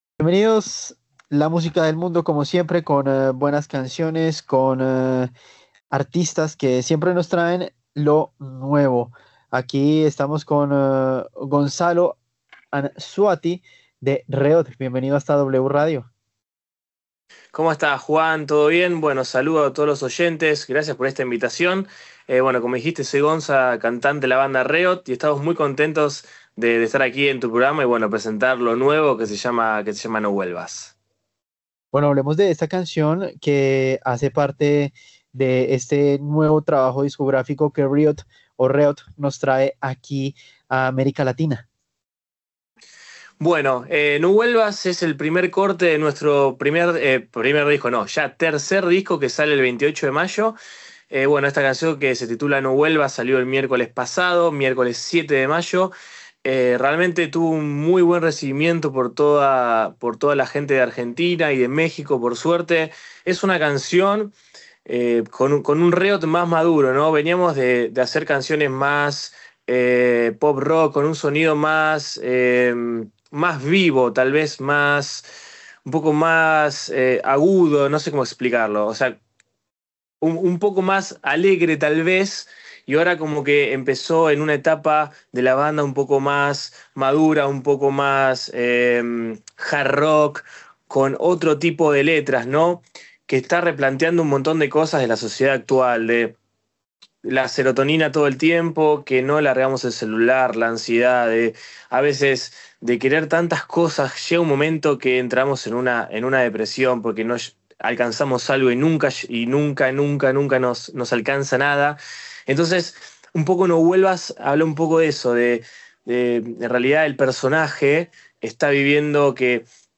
ENTREVISTA REOT